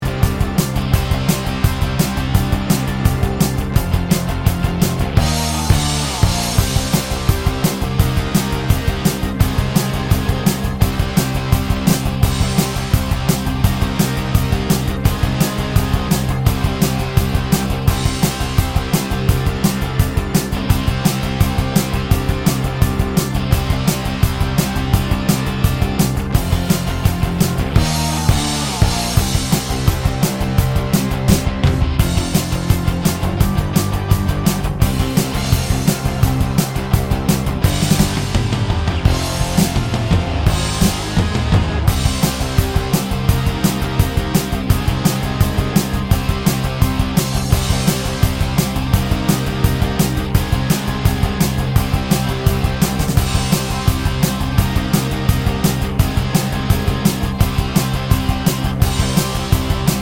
no Backing Vocals Rock 2:42 Buy £1.50